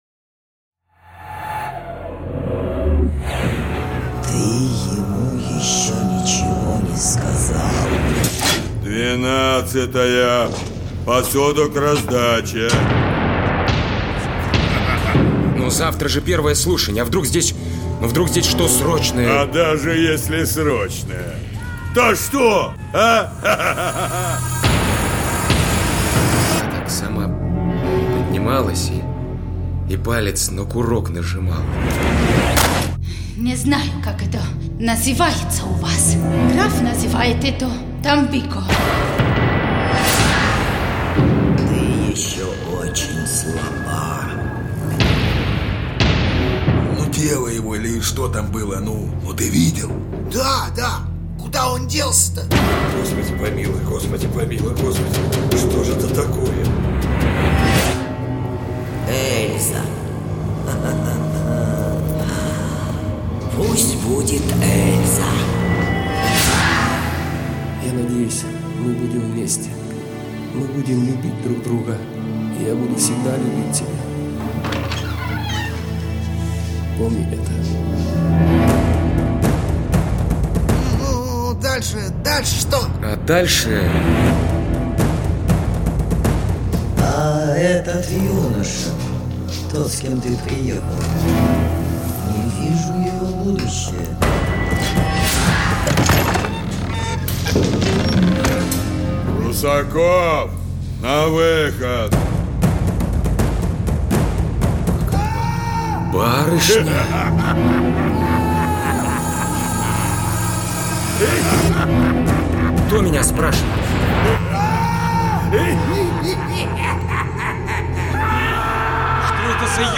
Аудиокнига Сделка. Бутыли Судьбы (2-й сезон, серии 09-16) | Библиотека аудиокниг